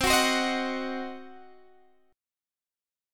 Cdim Chord
Listen to Cdim strummed